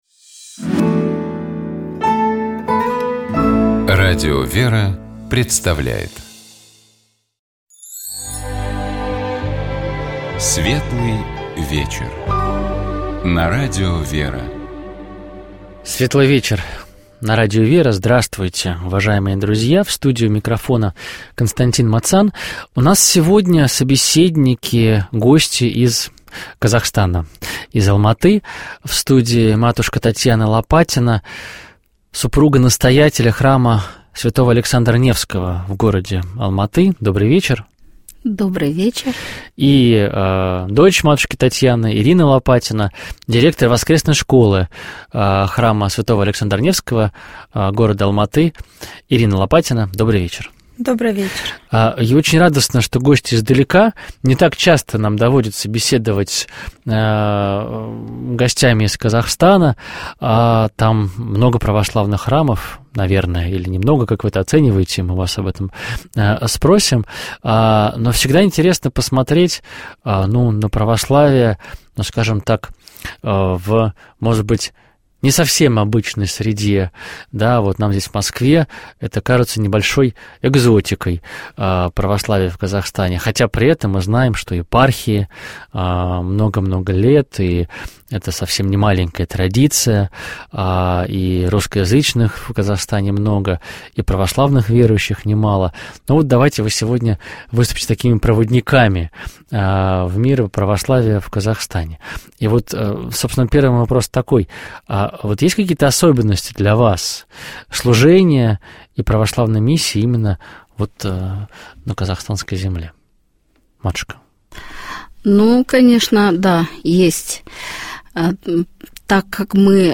Программа «Светлый вечер» — это душевная беседа ведущих и гостей в студии Радио ВЕРА. Разговор идет не о событиях, а о людях и смыслах.